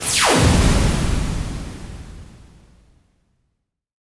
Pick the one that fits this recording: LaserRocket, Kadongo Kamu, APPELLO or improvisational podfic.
LaserRocket